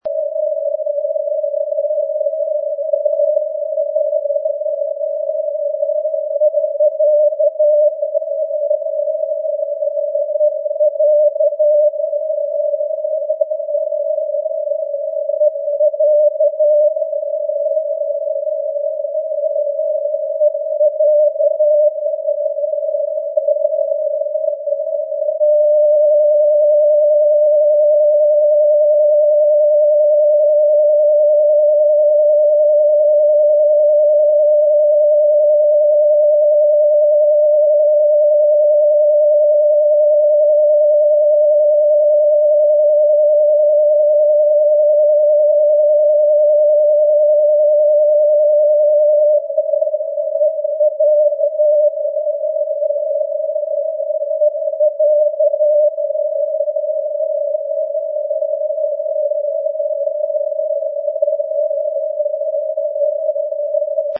in this recording of *EYa-309.5 you hear several times the callsign, and a long dash for direction finding